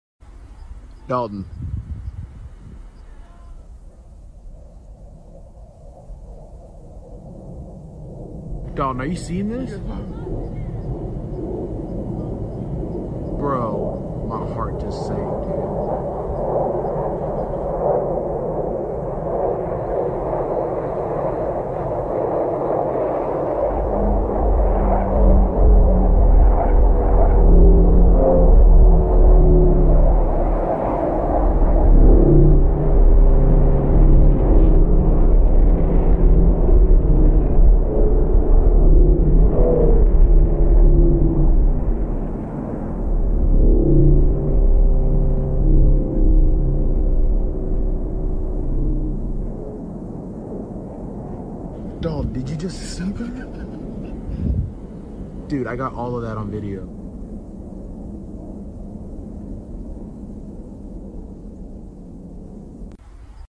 ufo flying all over las sound effects free download